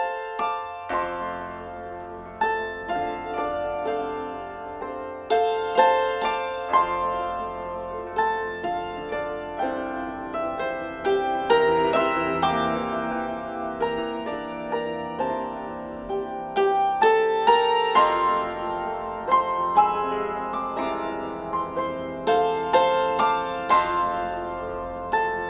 Hackbrettquartett